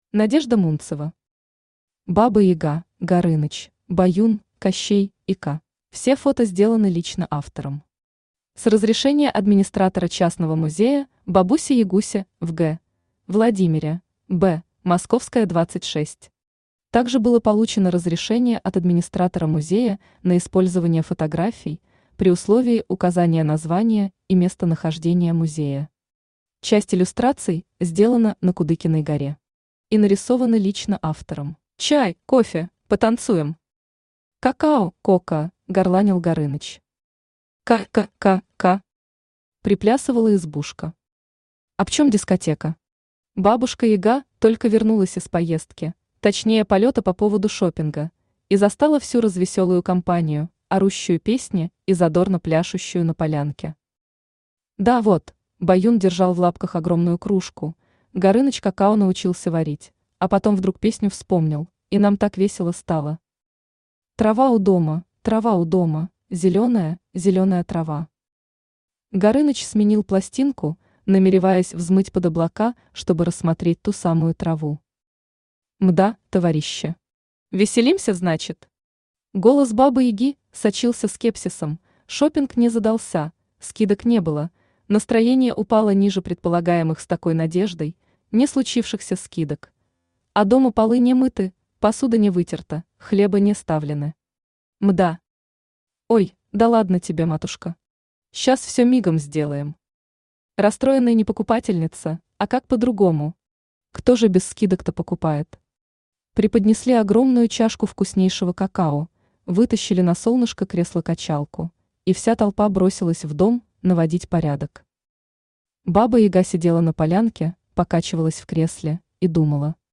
Аудиокнига Баба Яга, Горыныч, Баюн, Кащей и Ко | Библиотека аудиокниг
Aудиокнига Баба Яга, Горыныч, Баюн, Кащей и Ко Автор Надежда Михайловна Мунцева Читает аудиокнигу Авточтец ЛитРес.